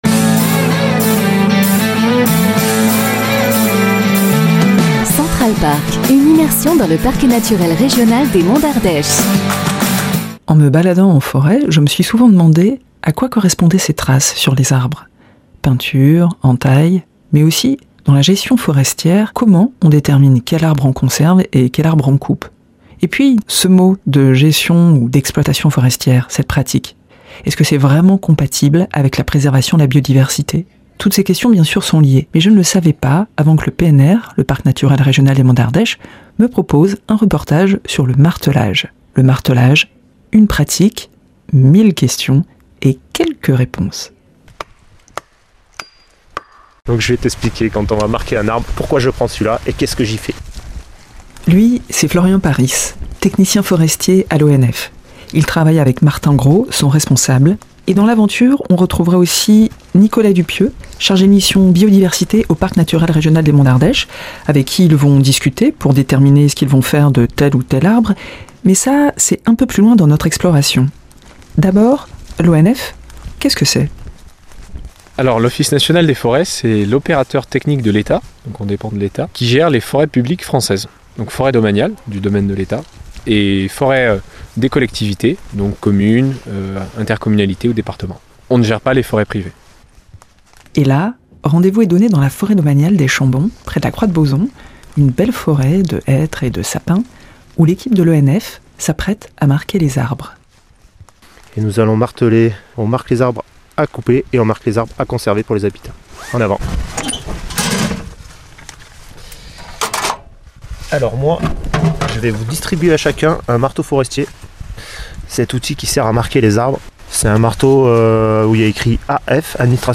Reportage dans la forêt des Chambons avec l'ONF et le Parc sur le martelage d'une parcelle de hêtres et de sapins. Et oui ! on frappe et on griffe les arbres, mais en douceur pour les identifier en prévision de la coupe ou de leur préservation.